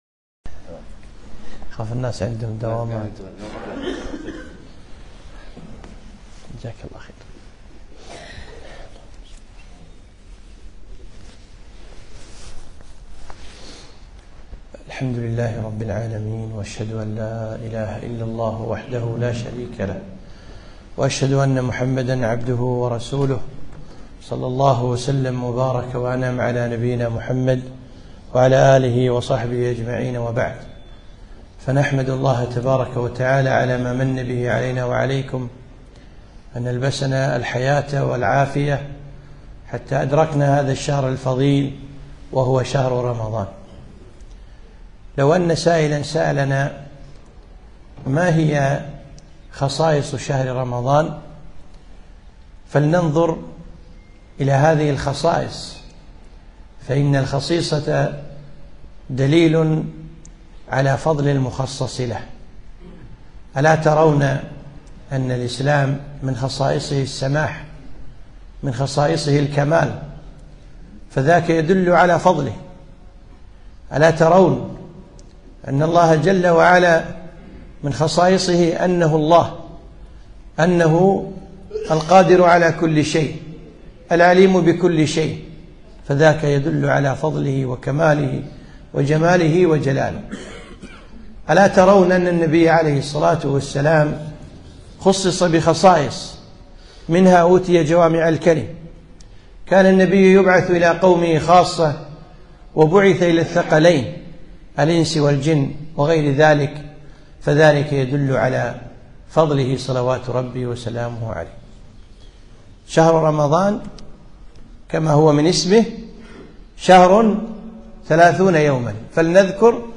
كلمة - ثلاثون خصيصة في شهر رمضان